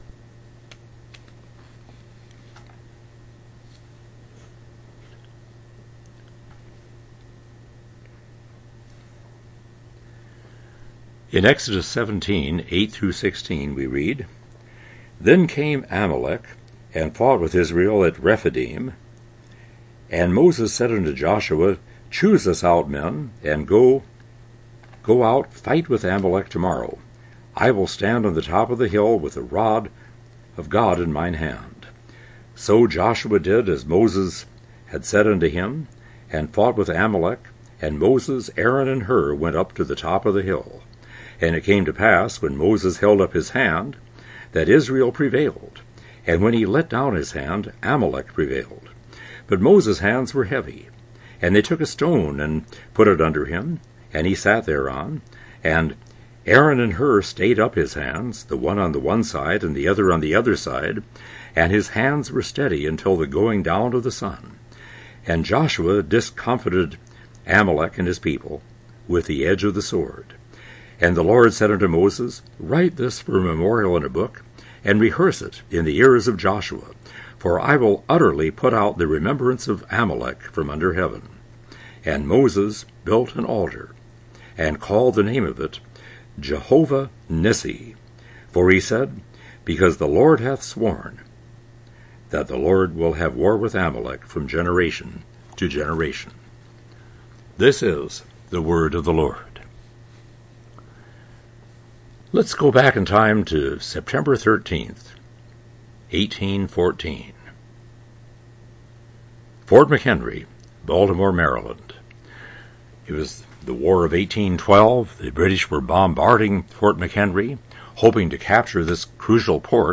Jehovah Nissi: Our Battle Flag — June 8, 2025 – Woodland Presbyterian Church